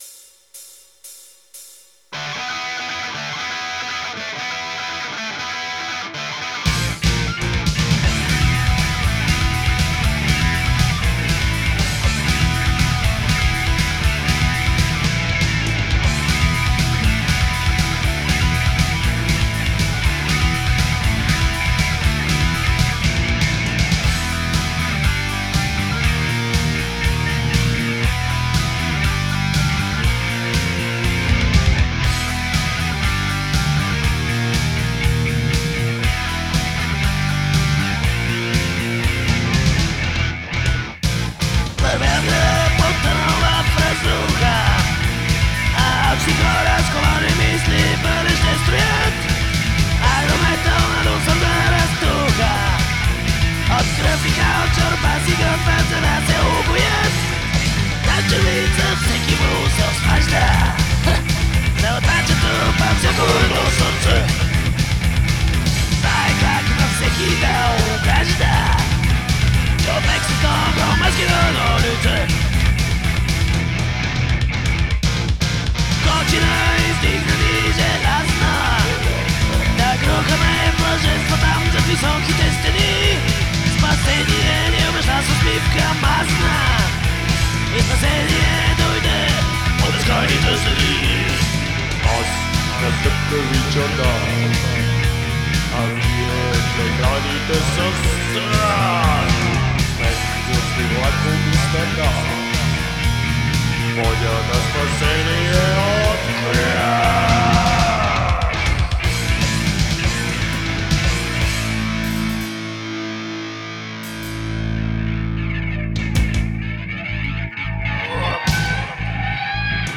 is an epic 7-minute metal piece